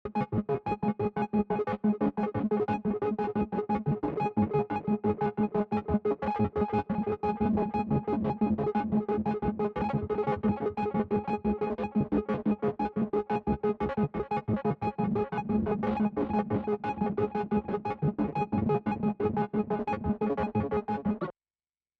Still toying around with the Hydra Explorer, but I’m surprised at how deep you can go with this little synth.
To give you an audible sense of the power of the mod matrix this was made with 17 of the available 32 sends. Mostly the 5 Lfo’s doing the work here. I just set one of the envelops at a really long release and tap the key.